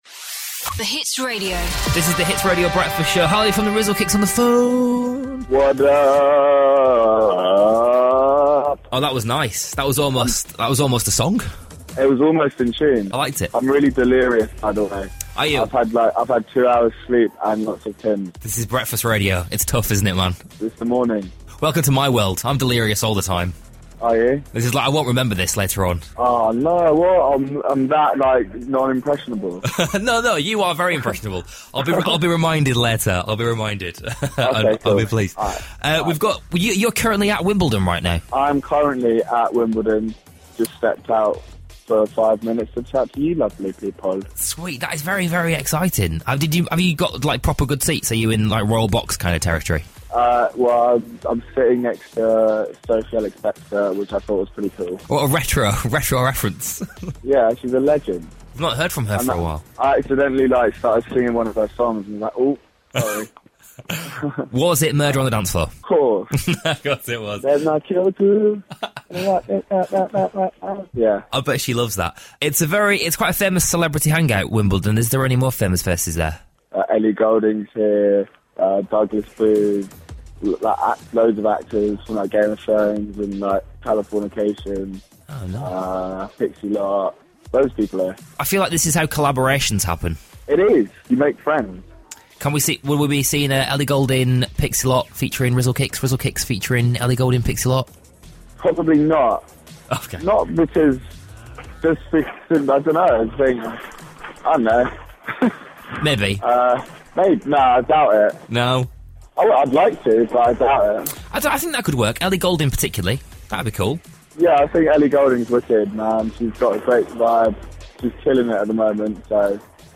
Harley from Rizzle Kicks called Darryl Morris this morning, from Wimbledon, to introduce their new song.